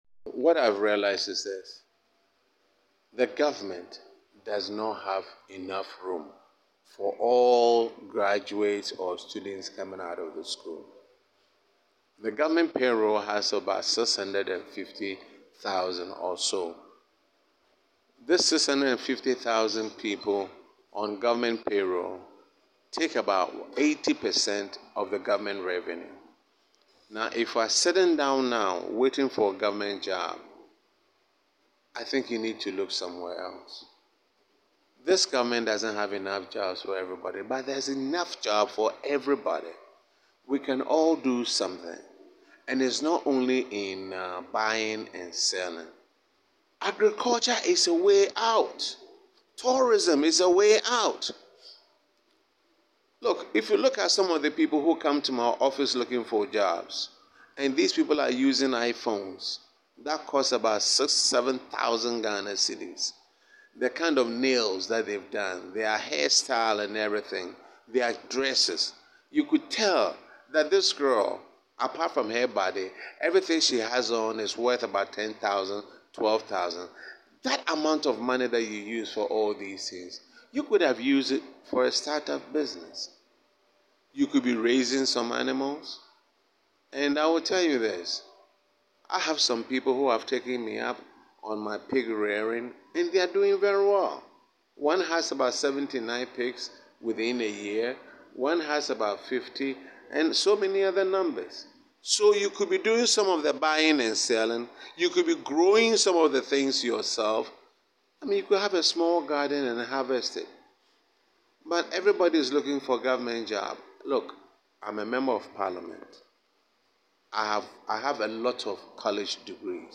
Speaking to the media at World Rural Women Day at Prang in the Pru West District of the Bono East Region, the Member of Parliament for double as the Deputy Minister for Roads and Highways Honourable Jalula has said, the restrictions played on Ghanaians during the Covid-19 pandemic, the closure of land and sea borders encouraged us to eat what we grow and compel us from exporting what we grow in Ghana.